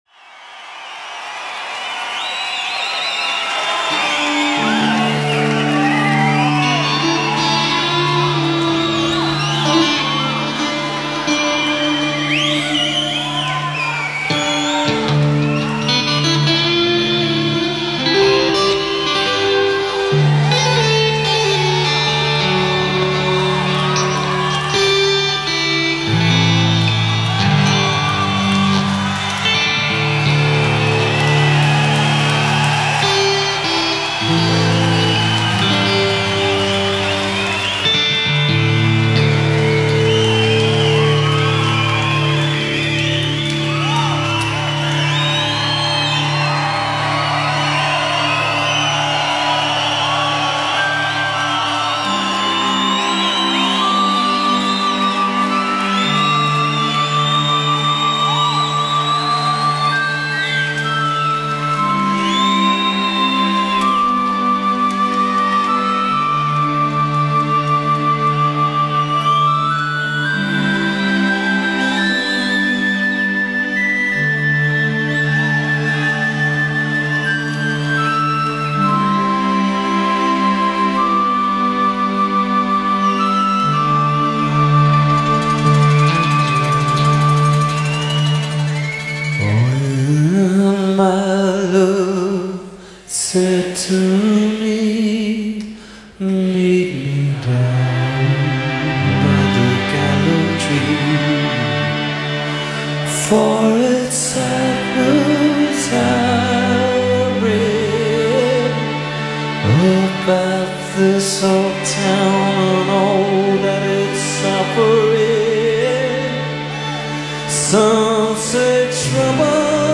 Royal Concert Hall, Glasgow 1995
Post-Punk-turned-Pop-Powerhouse
Some 80s Pop tonight.